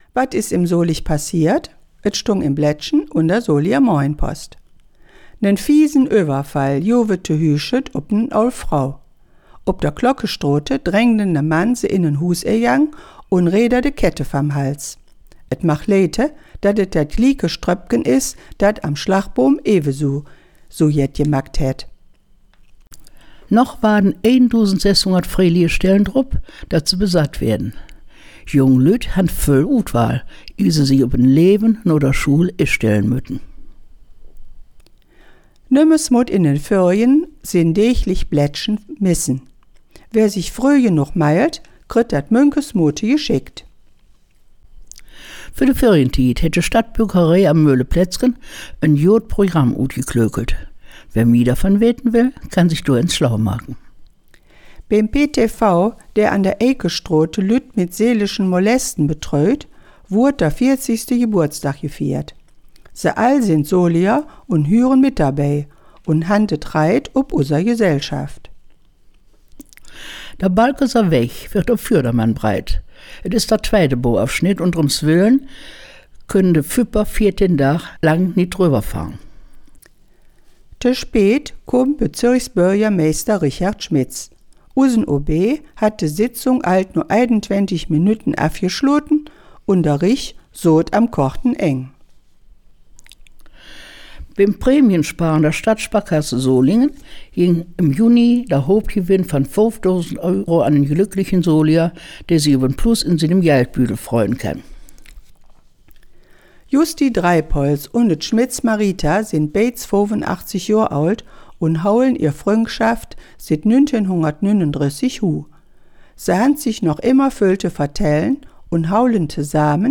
Der Nachrichten-Rückblick auf die KW27
Solinger-Platt-News-18kw27.mp3